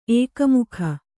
♪ ēkamukha